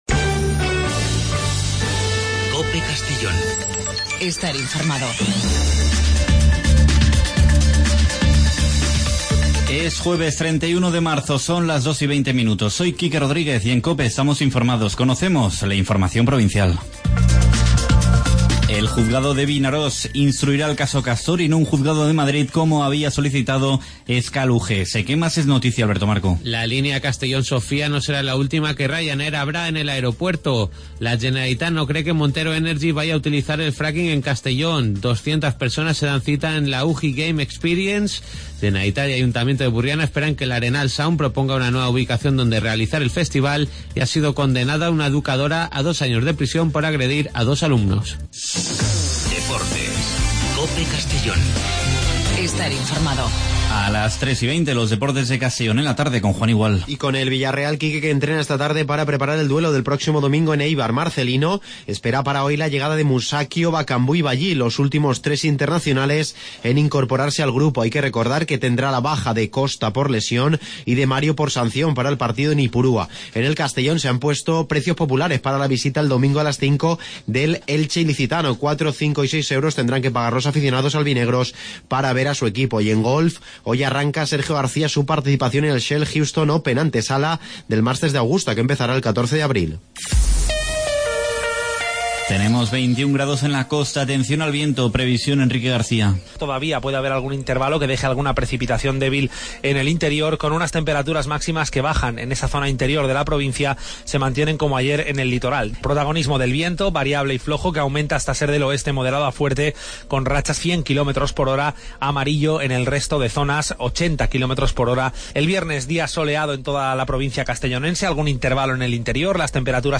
Espacio informativo a nivel provincial, con los servicios informativos de COPE en la provincia de Castellón.